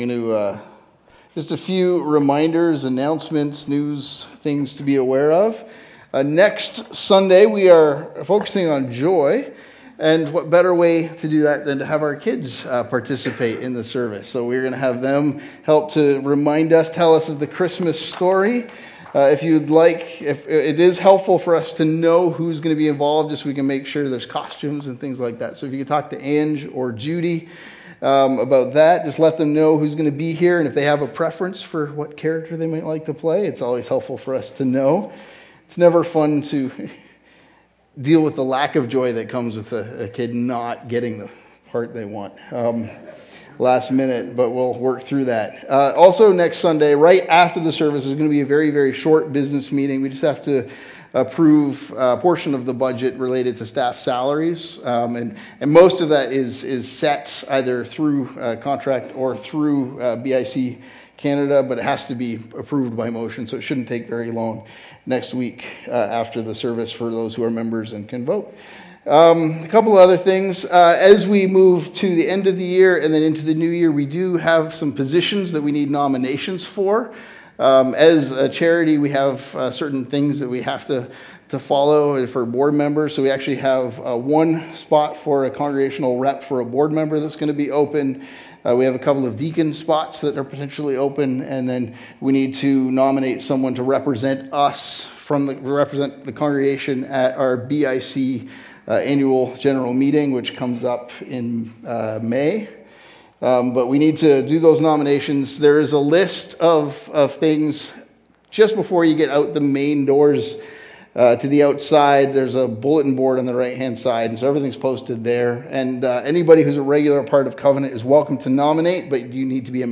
Sermons | Covenant Christian Community Church